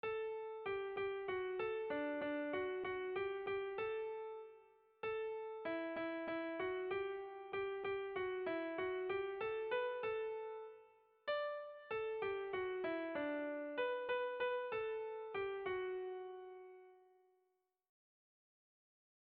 Sara < Lapurdi Garaia < Lapurdi < Euskal Herria
Egitura musikala
ABD